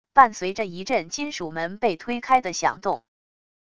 伴随着一阵金属门被推开的响动wav音频